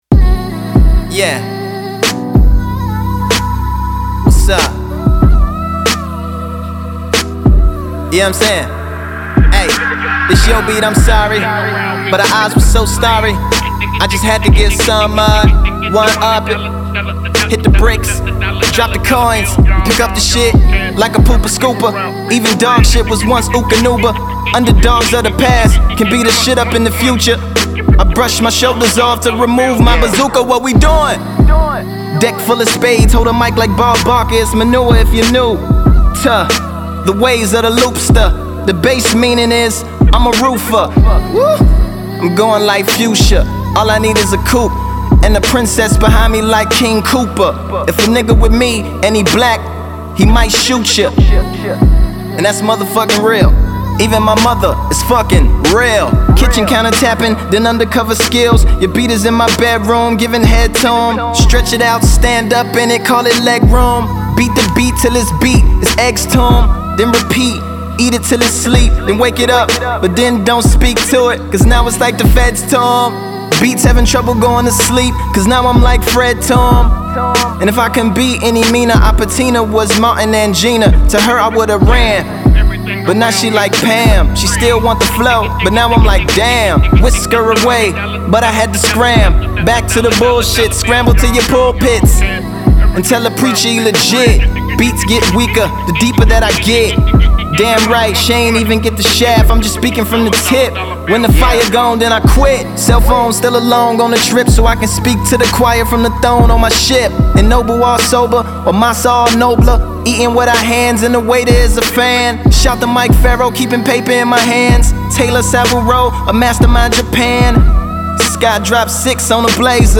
smooth instrumental